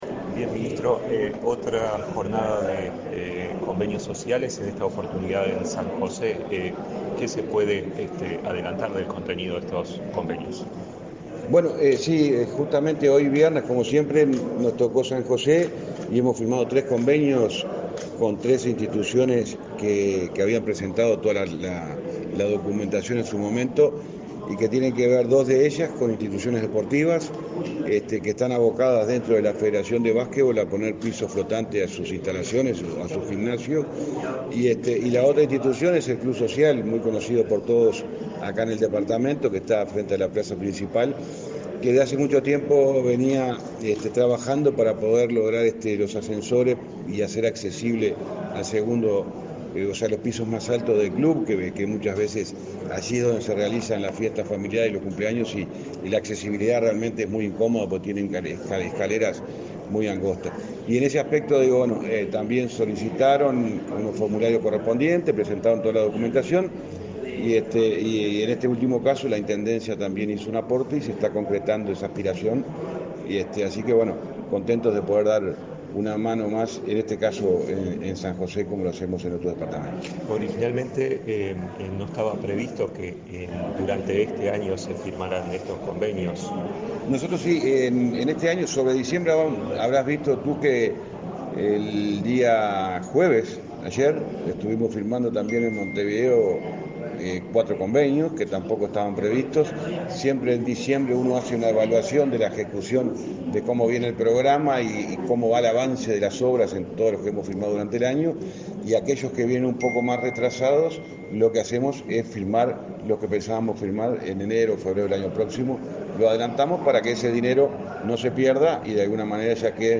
Declaraciones de prensa del ministro del MTOP, José Luis Falero
Declaraciones de prensa del ministro del MTOP, José Luis Falero 02/12/2022 Compartir Facebook X Copiar enlace WhatsApp LinkedIn El Ministerio de Transporte y Obras Públicas (MTOP) firmó convenios, este 2 de diciembre, con instituciones sociales y deportivas de San José. Tras el evento, el ministro Falero realizó declaraciones a la prensa.